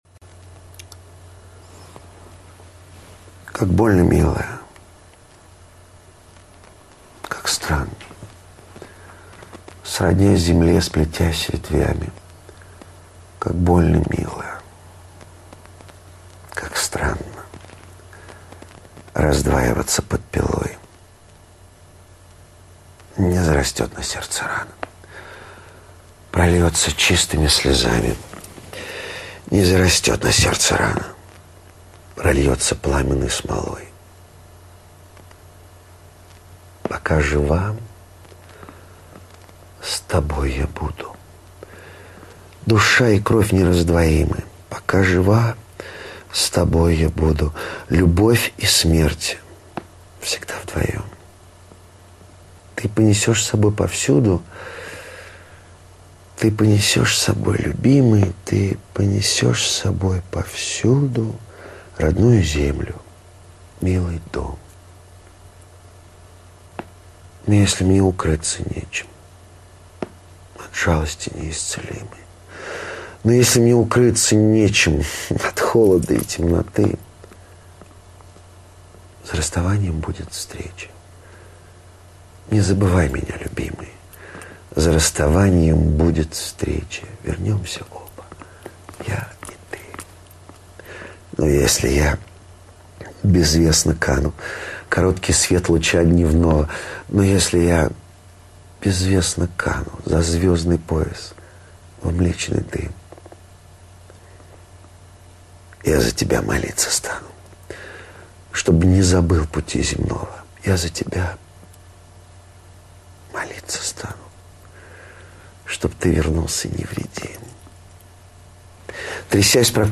Максим Аверин(декламация)   Демарин И.-Шведова И.